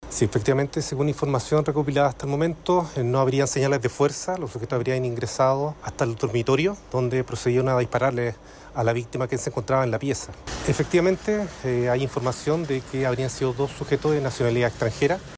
El fiscal ECOH, Cristián Soto, comentó que fueron dos personas quienes realizaron el ataque.